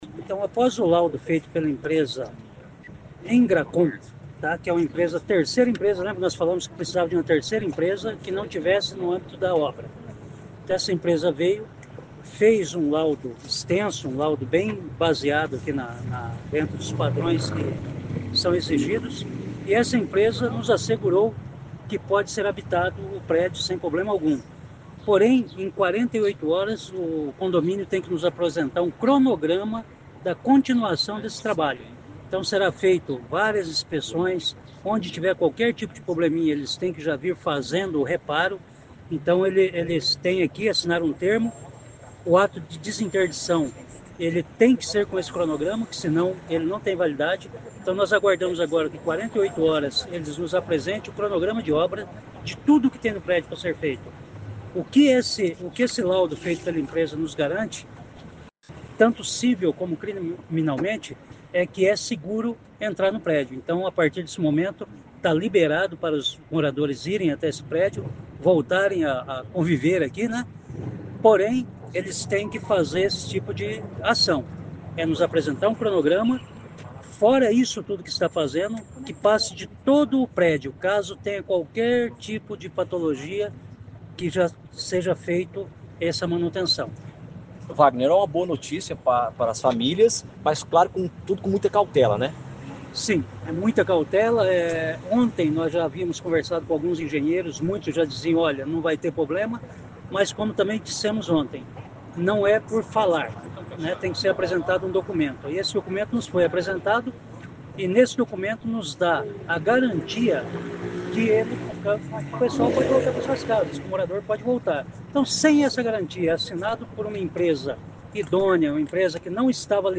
Em entrevista coletiva, o  secretário da Defesa Civil, Vagner Mussio, explicou o que acontece a partir de agora.